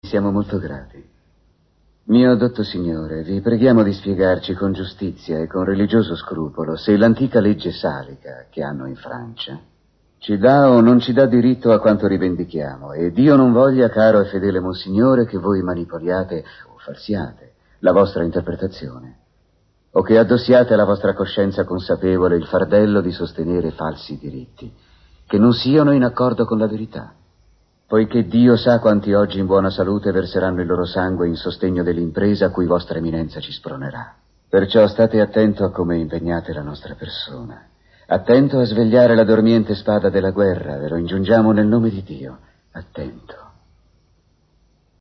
voce di Massimo De Francovich nel film TV "Tutto Shakespeare: Enrico V", in cui doppia David Gwillim.